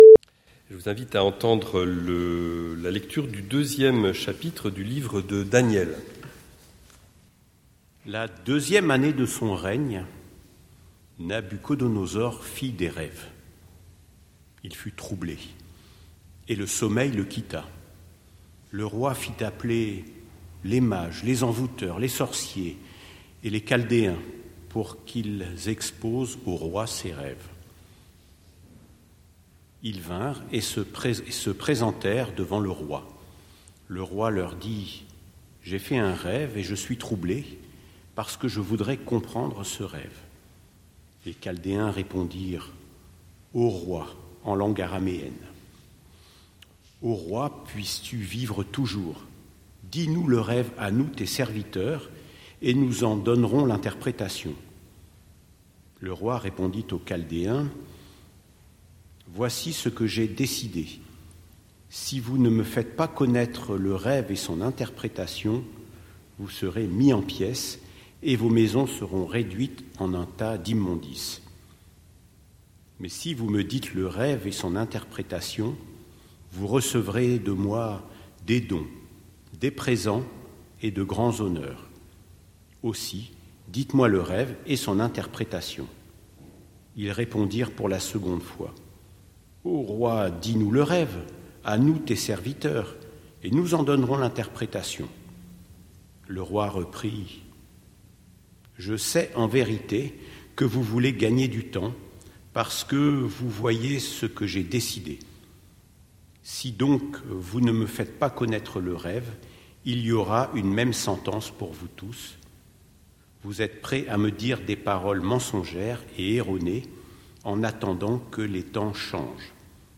Prédication du 25/01/2026